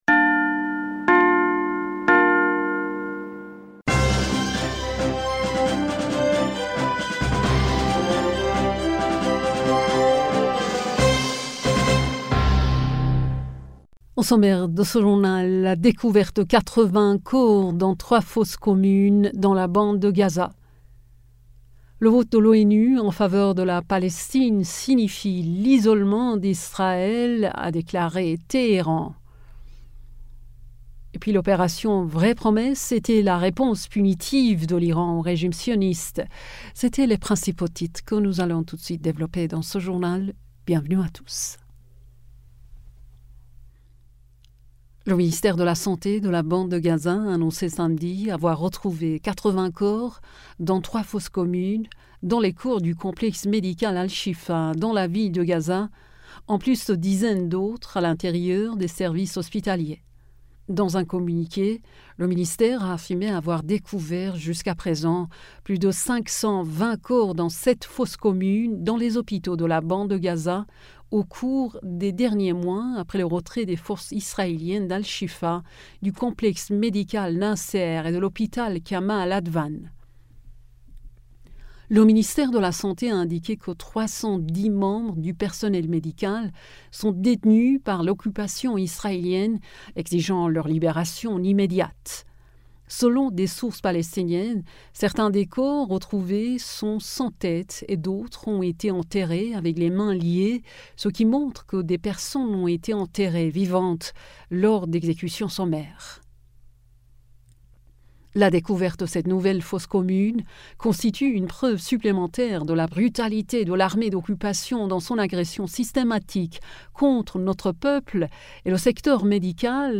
Bulletin d'information du 12 Mai